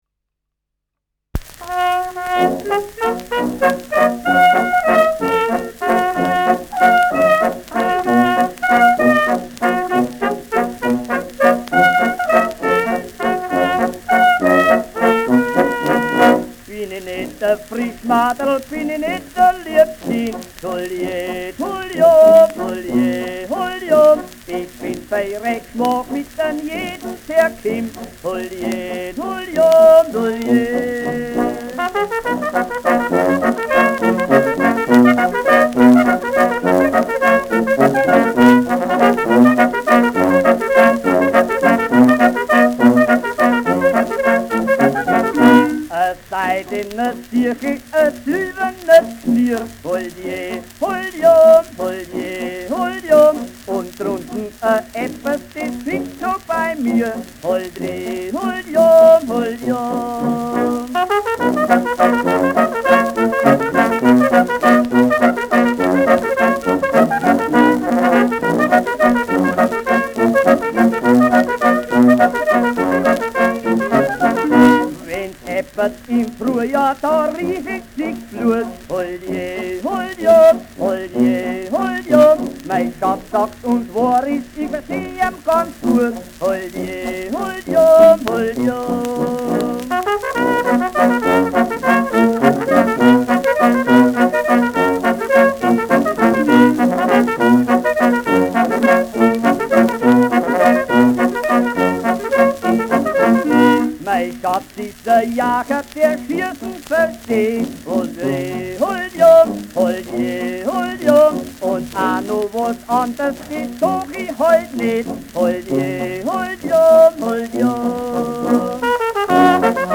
Schellackplatte
Tonrille: Abrieb
leichtes Knistern
Fränkische Bauernkapelle (Interpretation)